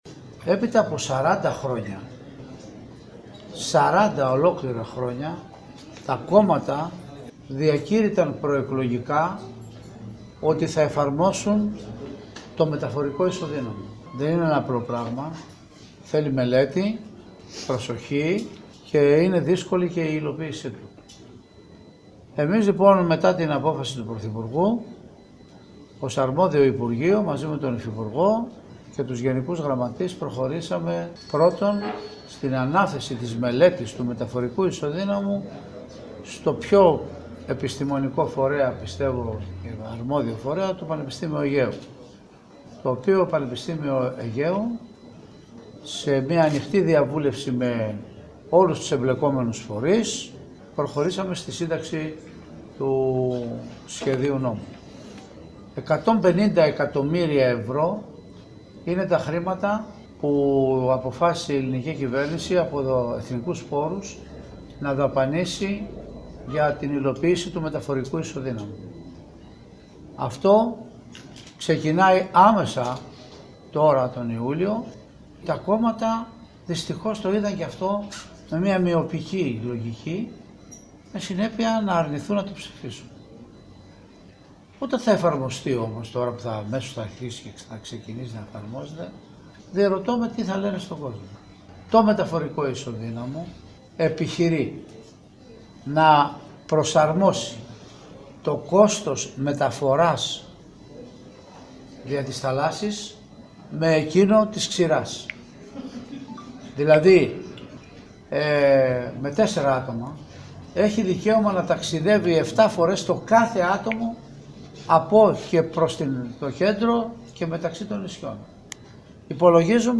Ακούστε απόσπασμα των δηλώσεων του κ. Κουρουμπλή για το μεταφορικό ισοδύναμο, στον σύνδεσμο που ακολουθεί: